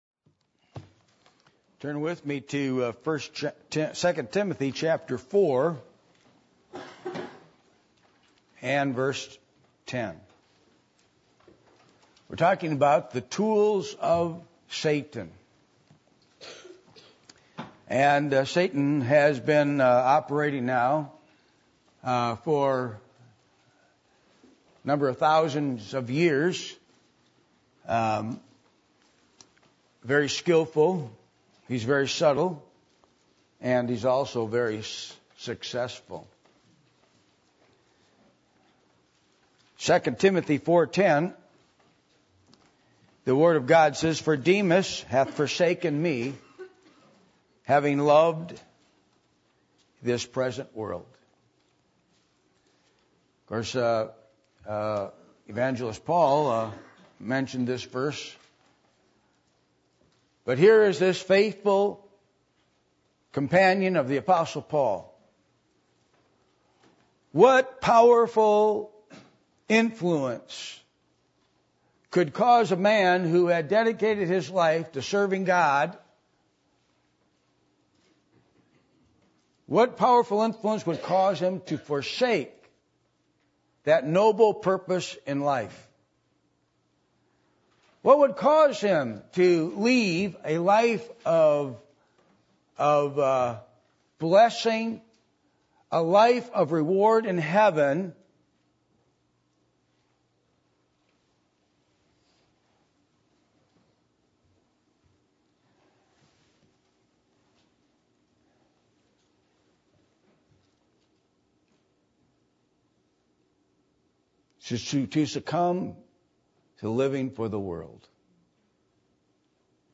2 Timothy 4:10 Service Type: Sunday Morning %todo_render% « Things To Expect When God Sends Revival Why Are Christians Ashamed To Be Different?